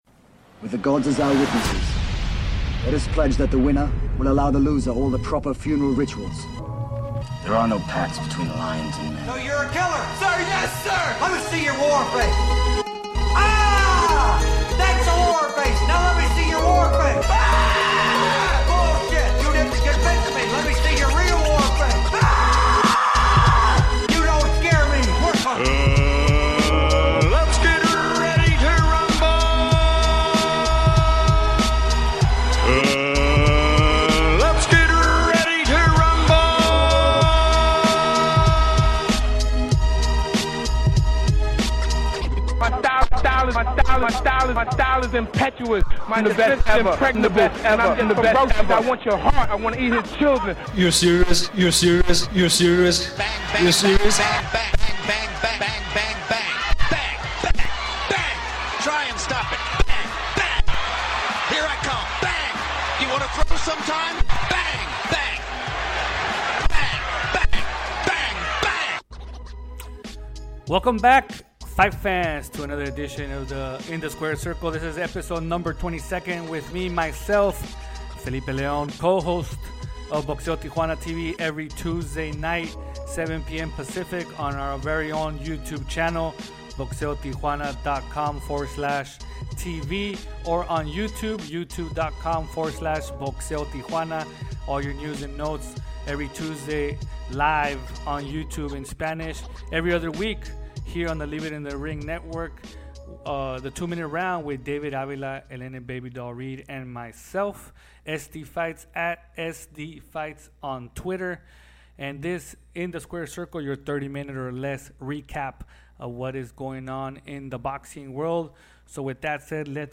with a fast pace style of 30 minutes or less